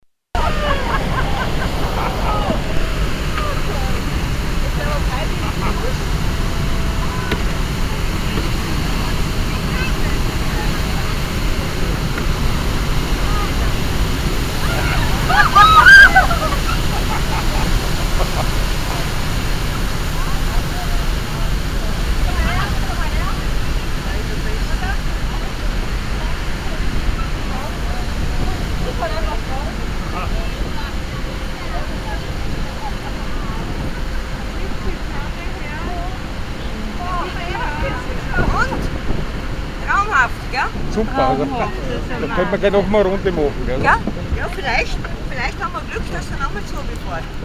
Beim Rheinfall. Wir sind mit dem Schiff unterwegs und fahren sehr nahe an dem Wasserfall heran.. Der Rheinfall befindet sich in der Schweiz im Kanton Schaffhausen.